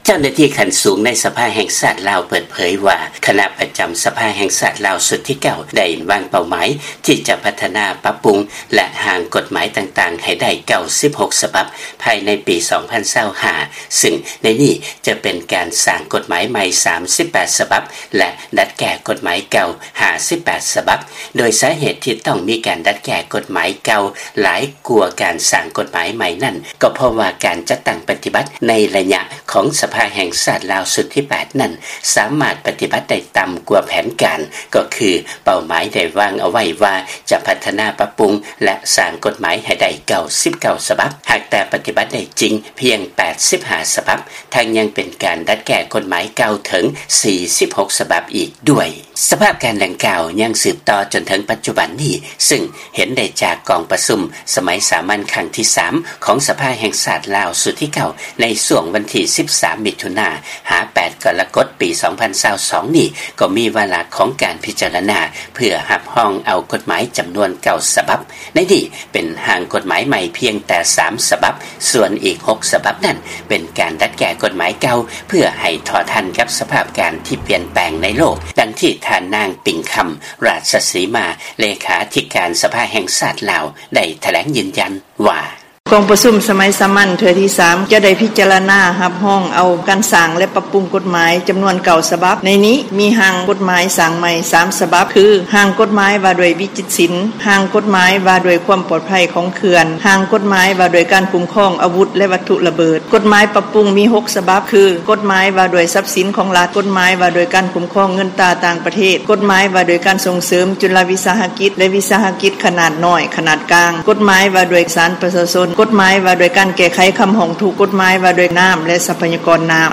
ເຊີນຟັງລາຍງານ ກົດໝາຍທີ່ບໍ່ໄດ້ມາດຕະຖານ ຄືສາເຫດທີ່ເຮັດໃຫ້ລາວ ຕ້ອງດັດແກ້ກົດໝາຍເກົ່າ ຫຼາຍກວ່າການສ້າງກົດໝາຍໃໝ່